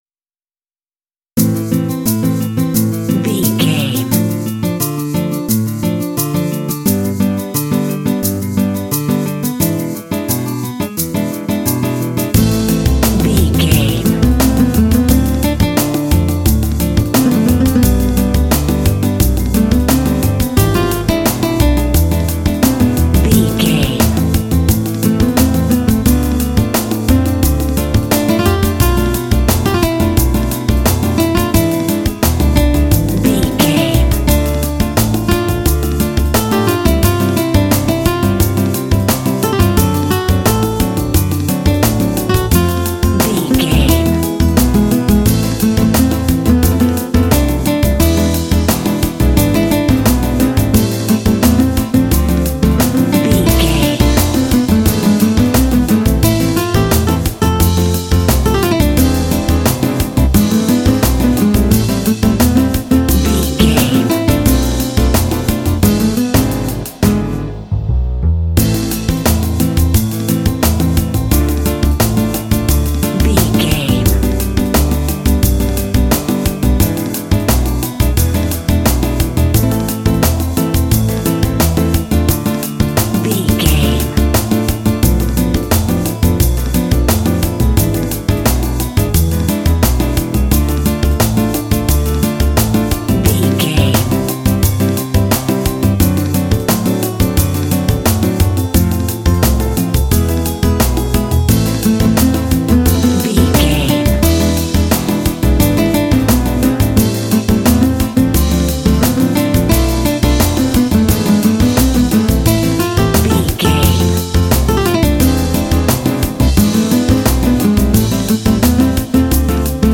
Aeolian/Minor
smooth
sensual
acoustic guitar
drums
percussion
double bass
flamenco
mambo
rhumba